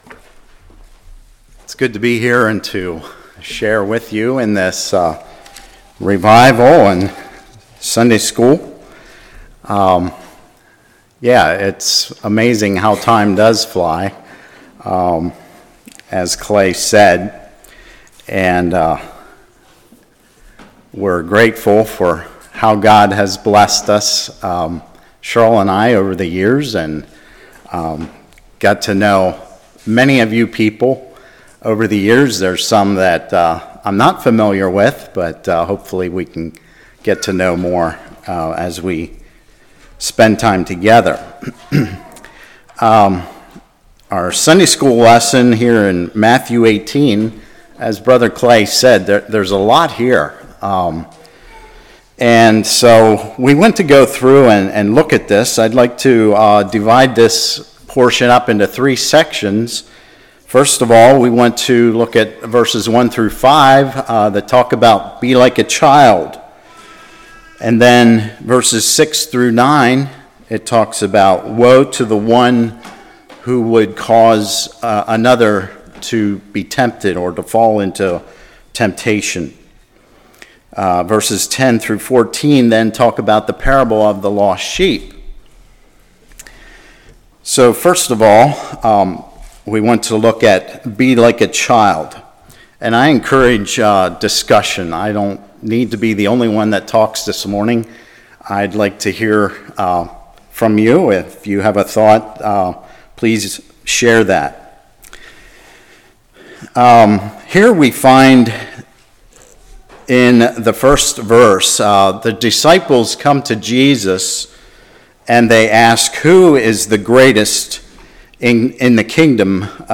Matthew 18:1-14 Service Type: Sunday School Who is the Greatest Humility Childlike Faith Heart Change « The Plagues of Egypt Hope!